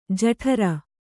♪ jaṭhara